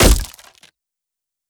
Hit_Wood 01.wav